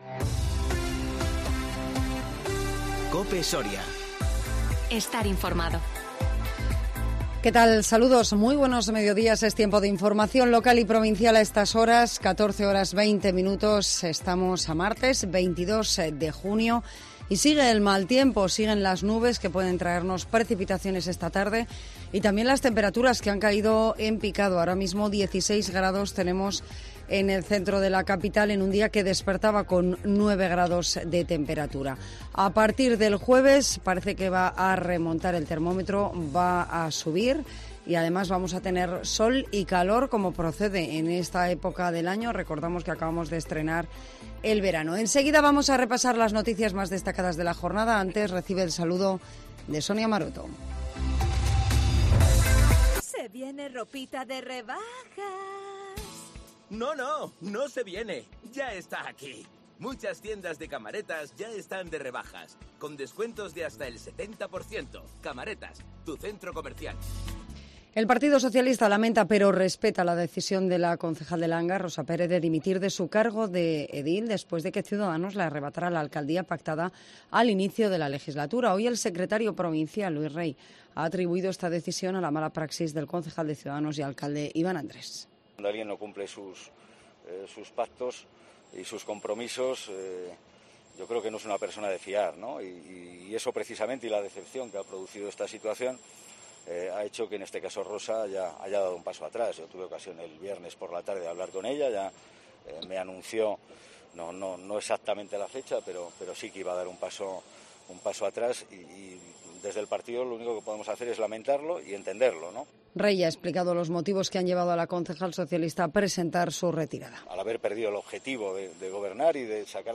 INFORMATIVO MEDIODÍA 22 JUNIO 2021